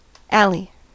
Ääntäminen
IPA : /ˈæli/